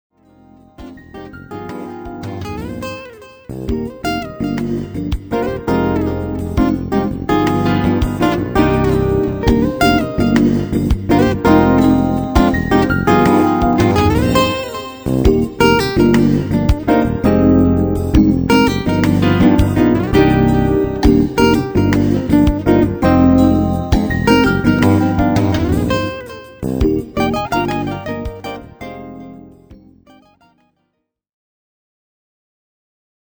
and contemporary jazz.
His nylon string guitar sings over original
hypnotic grooves, complimenting many venues